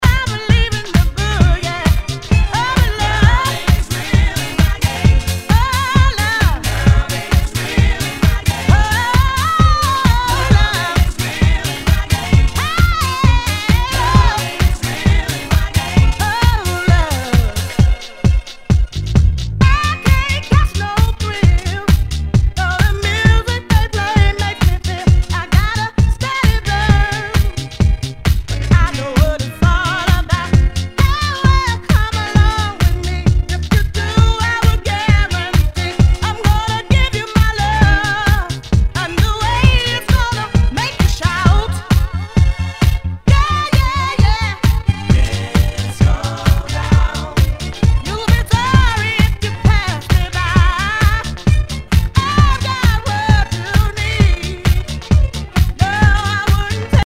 HOUSE/TECHNO/ELECTRO
ナイス！ディスコ・ヴォーカル・ハウス！